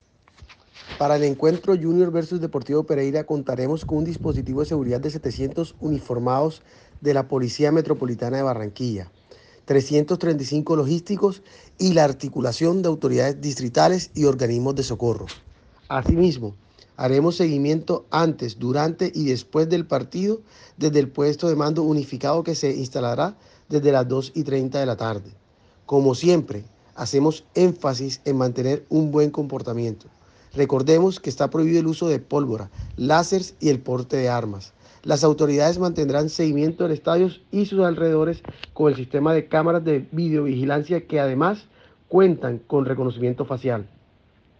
NELSON PATRÓN – SECRETARIO GOBIERNO
AUDIO-SEC-GOBIERNO-NELSON-PATRON.mp3